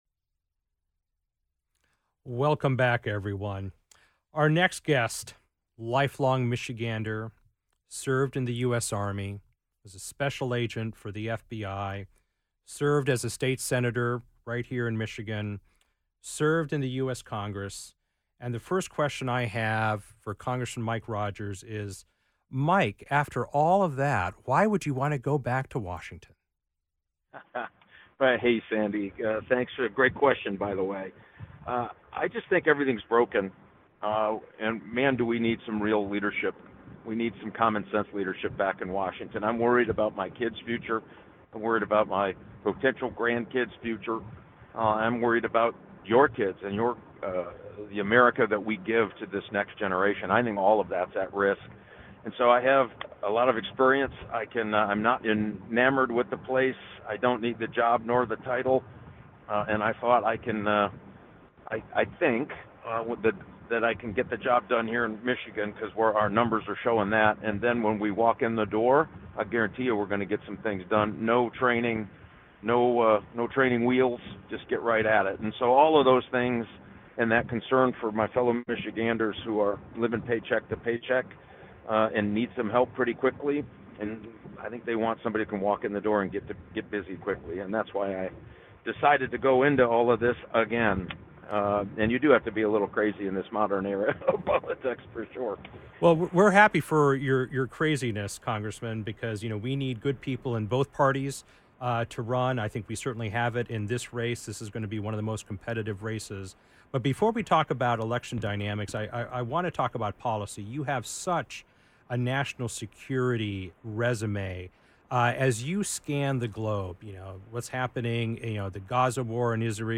Many guests shared timely insights on EVs and the industry transition underway during their conversations.